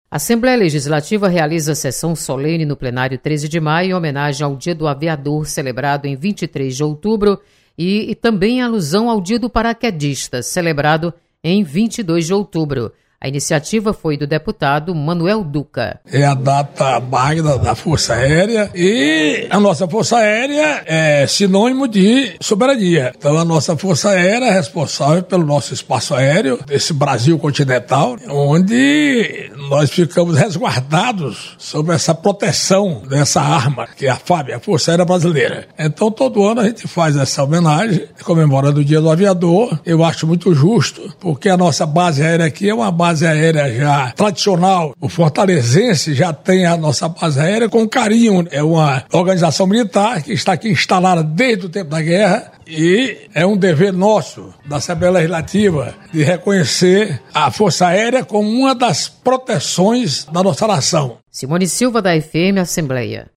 Sessão Solene